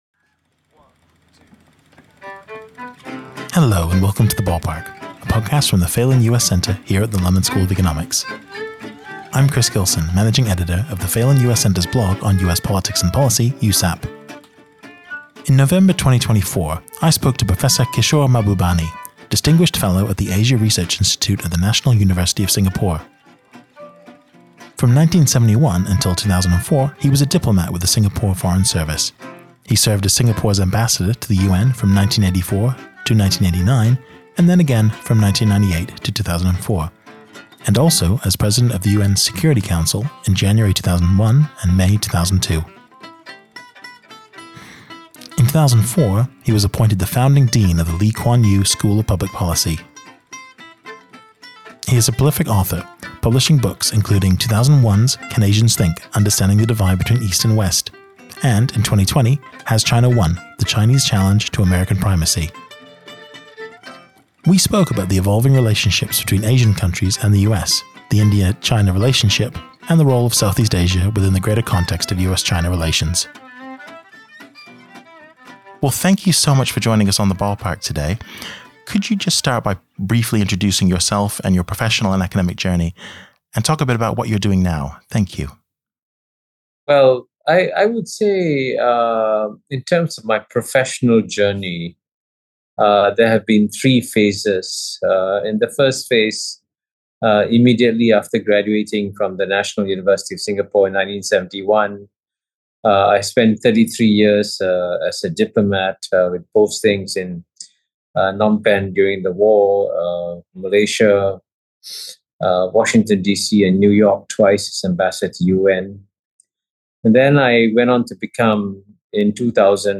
In November 2024 the Phelan US Centre spoke to Professor Kishore Mahbubani, Distinguished Fellow at the Asia Research Institute at the National University of Singapore.